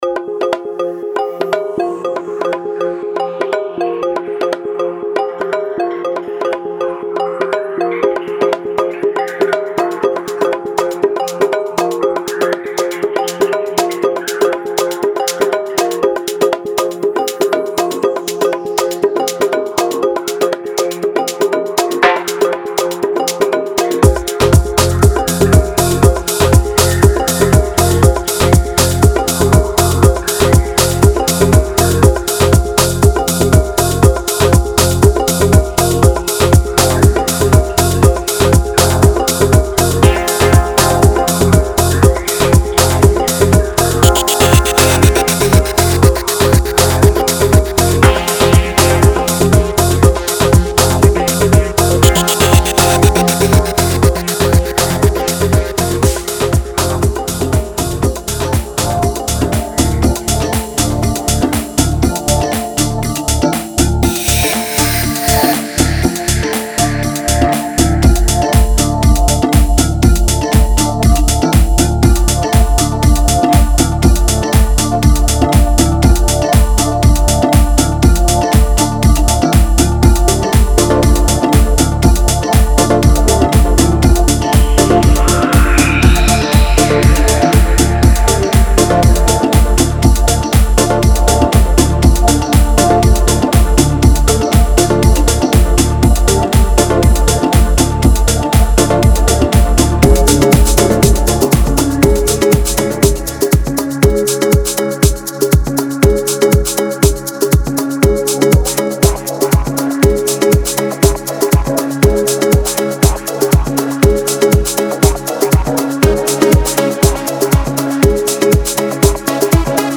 Genre:Afro House
BPMは安定した120に設定されており
デモサウンドはコチラ↓